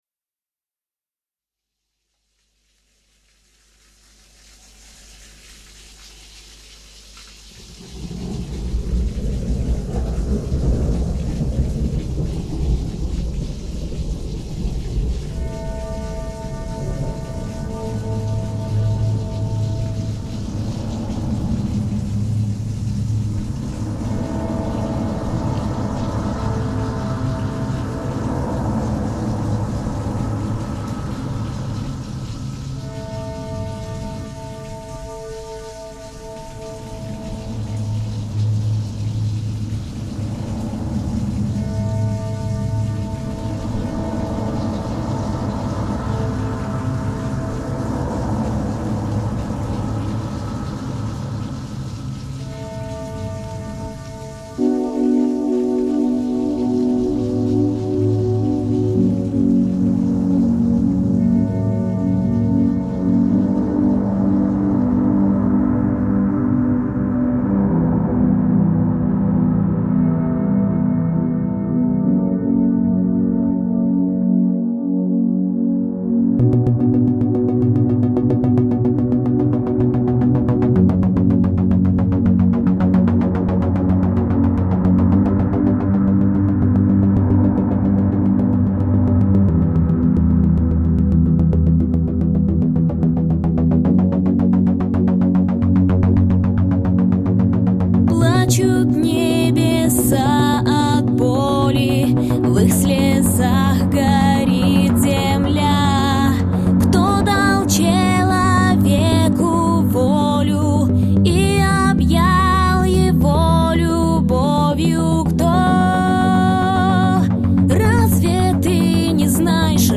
Tags: euro-dance techno pop fun beat